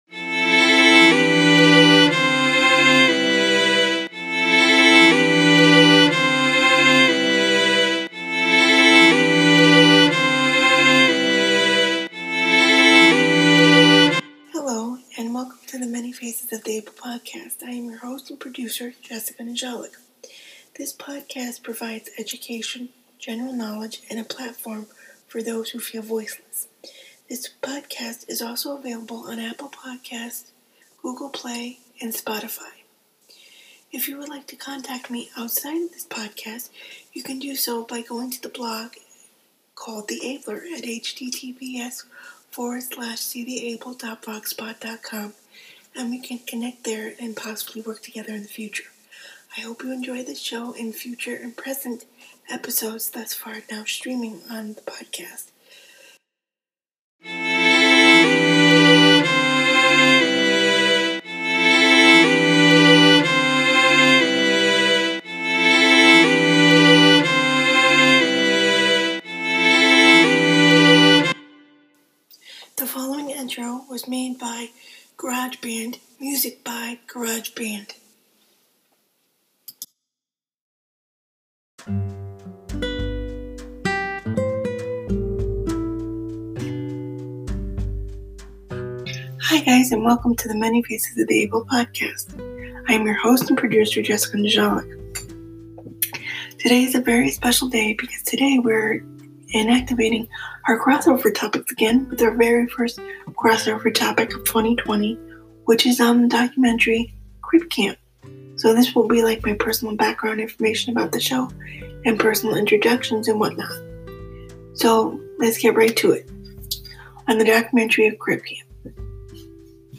On Air Advocate Interview